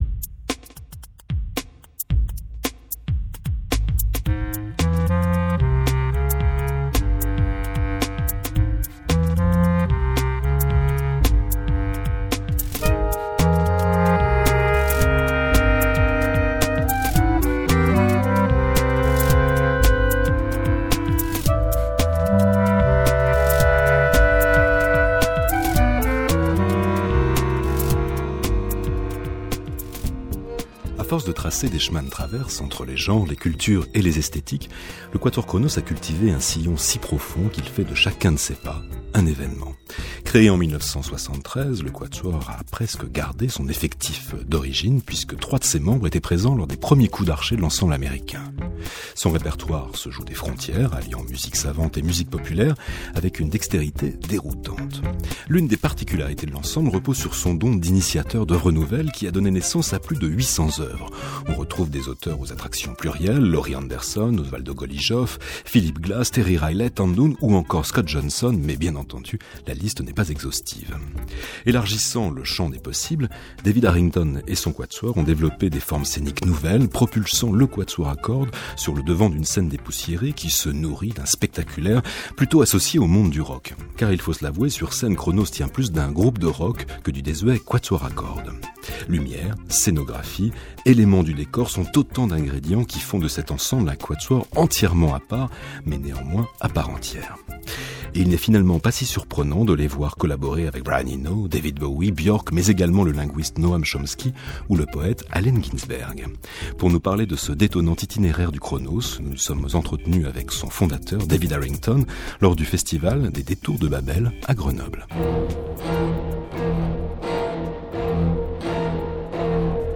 Un entretien avec David Harrington, co-fondateur du quatuor à cordes Kronos Quartet, ça parle musique (évidemment), cinéma et politique américaine.